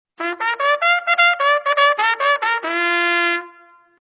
Tromba motivo militare
Breve inciso di tromba tipica melodia in uso nell'esercito.
tromba1_miltare.mp3